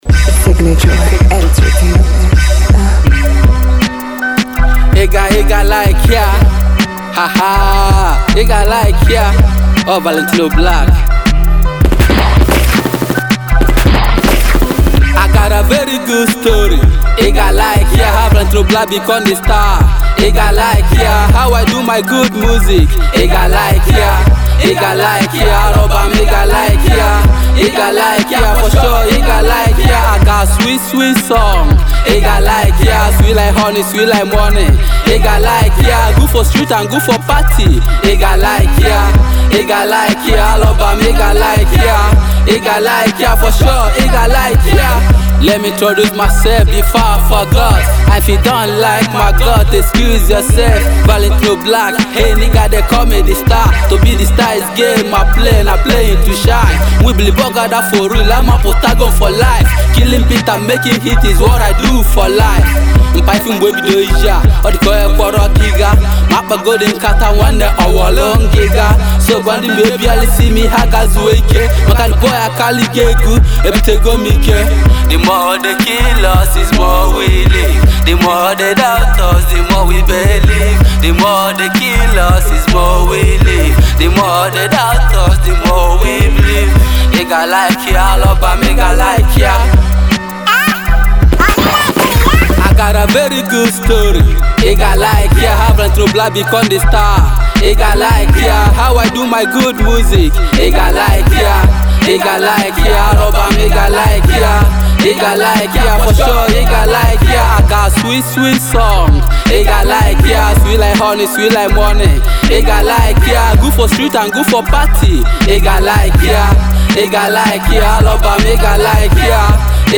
Igbo Music, indigenous Hip-Hop
Indigenous Igbo Rapper